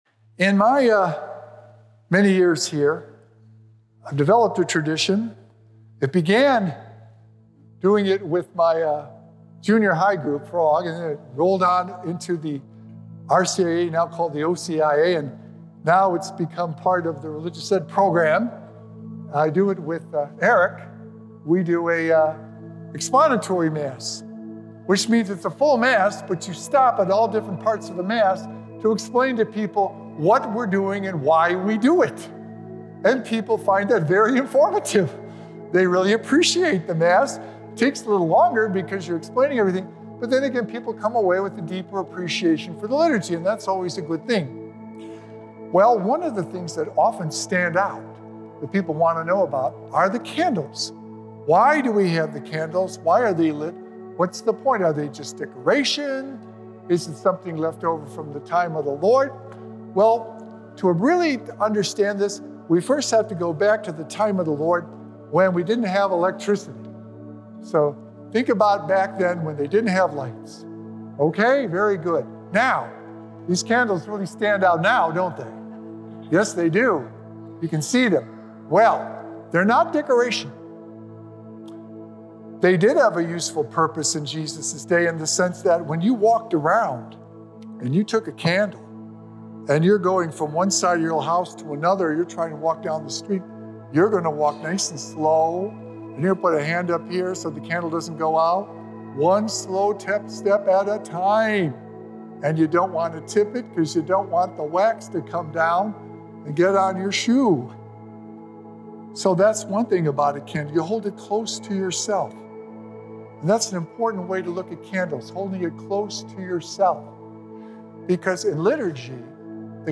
Sacred Echoes - Weekly Homilies Revealed
We light the Hope Candle in contemplation of Christ’s Birth and Second Coming, hopeful that He will bring us His Glory and eternal life! Recorded Live on Sunday, November 30th, 2025 at St. Malachy Catholic Church.